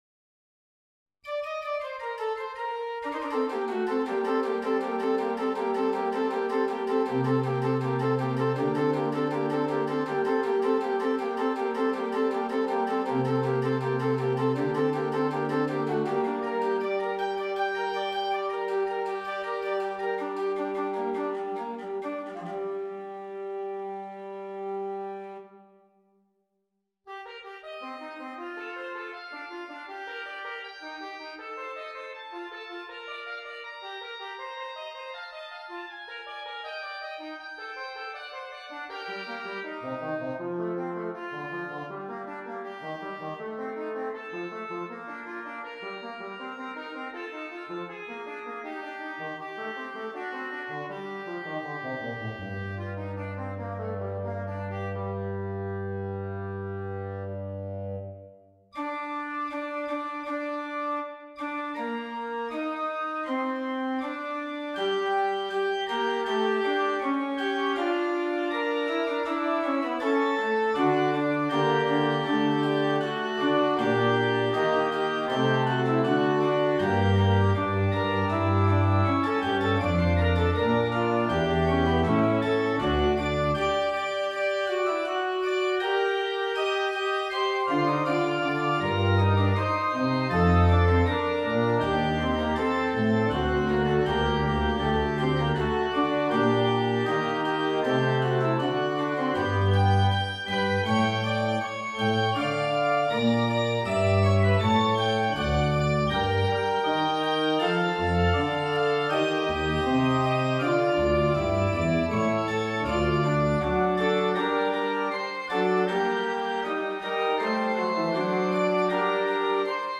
in Piano Music, Solo Keyboard
Preambulum in g for manualiter.
Written in the north german school.
Maybe the ending also is a little abrupt.
Either way, the counterpoint is mostly top notch, so I can't really say anything about the technical execution.